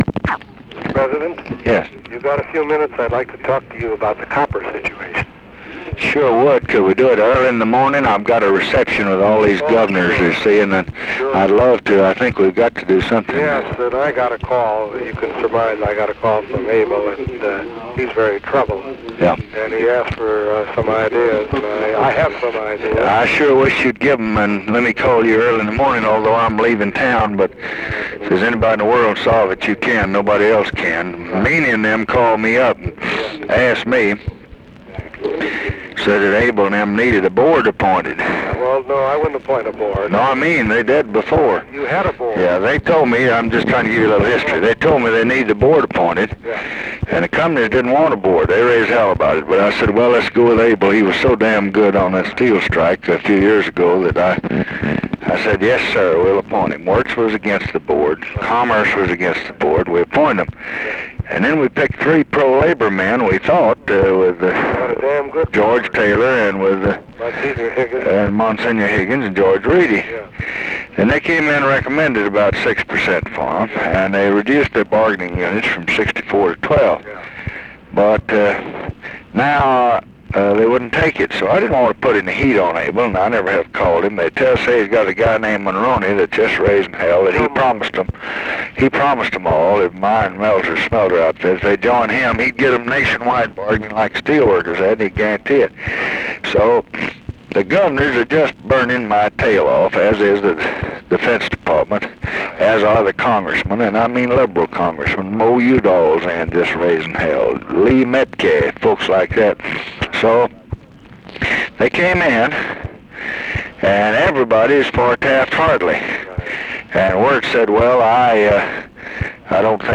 Conversation with ARTHUR GOLDBERG, March 1, 1968
Secret White House Tapes